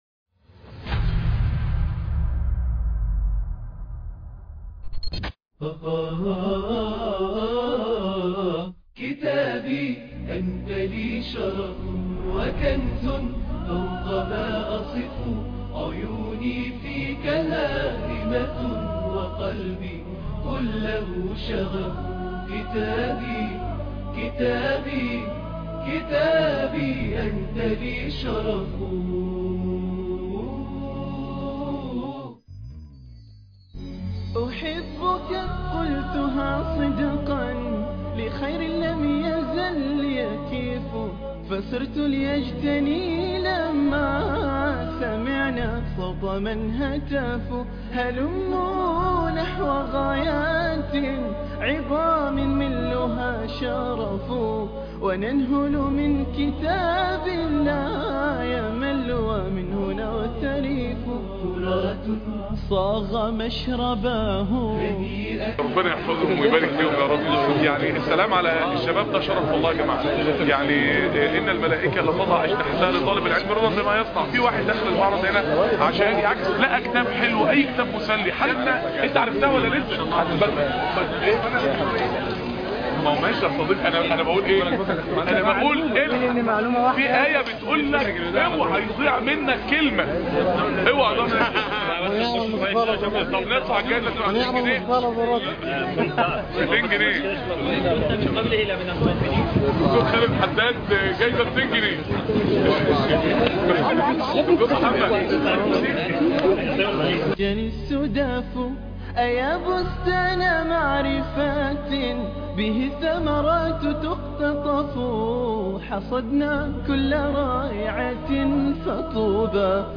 لقاءات
داخل معرض الكتاب